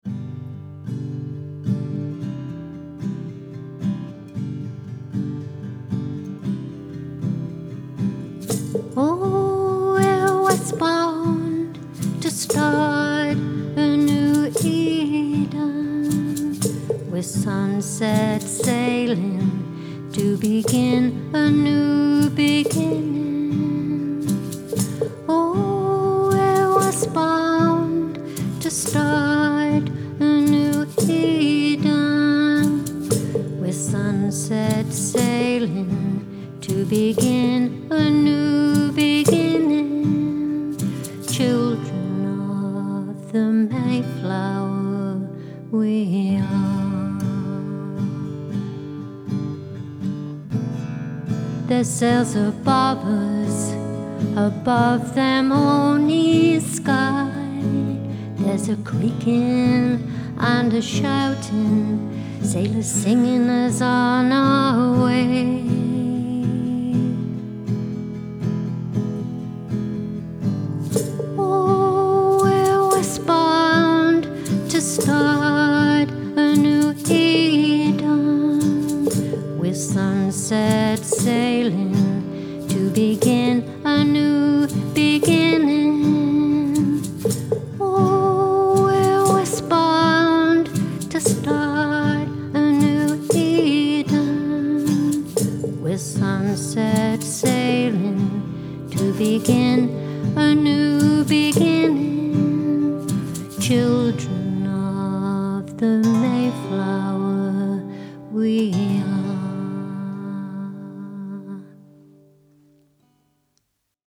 Children-Of-The-Mayflower-VOCAL-JTdg.mp3